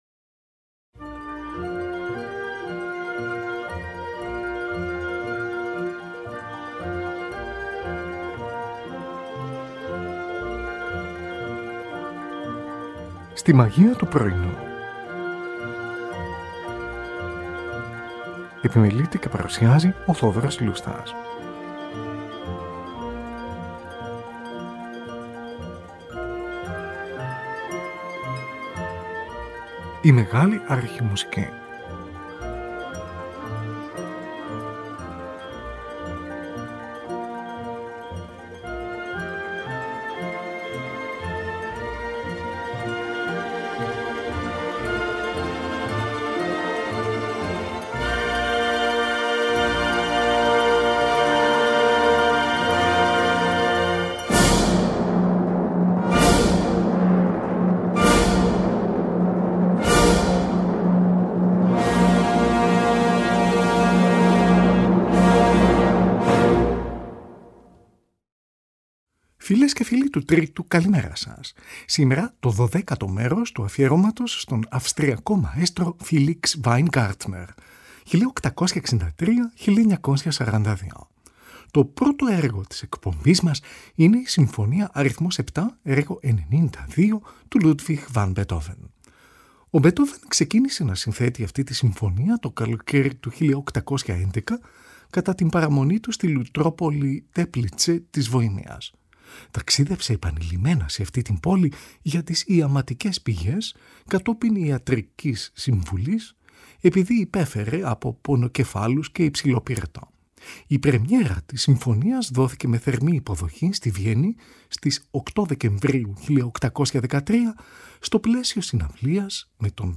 συμφωνία αρ. 7
στη Μεγάλη Αίθουσα του Musikverein.
σε σολ ελάσσονα